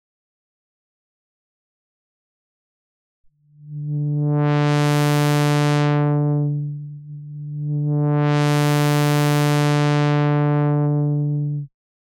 Voici une illustration audio du résultat de ces modulations sur une seule note le Ré2 (D2) sur laquelle j’ai appliqué la modulation ci-dessous avec le MIDI CC#2 comme source.  J’ai sélectionné une forme d’onde en dent de scie (Saw) sans aucun autre traitement, la seule et unique chose qu’il faut faire avant tout c’est de désactiver la vélocité ou d’en réduire la sensibilité au maximum (afin de ne pas être obliger d’attaquer la note pour que le son soit produit.
Une seconde fois la modulation CC#2 ne s’applique que sur la fréquence de coupure du filtre passe-bas (on constate cette fois que non seulement le timbre est affecté mais également le volume, le filtre éliminant certaines harmoniques mais aussi l’énergie contenue dans la partie du spectre coupée.
note: le synthé virtuel utilisé pour cette démo est le Falcon d’UVI un synthé virtuel particulièrement adapté pour les contrôleurs à vent.
CC2-on-Cut-Off-Only.mp3